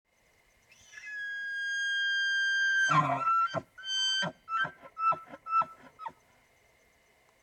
Elch Geräusche
Männliche Elche stoßen tiefe, röhrende Laute aus, um Weibchen anzulocken und Rivalen abzuwehren. Weibchen geben häufig hohe, klagende Rufe von sich, um mit ihren Kälbern oder Männchen zu kommunizieren.
Elch-Geraeuschhe-Wildtiere-in-Deutschland.mp3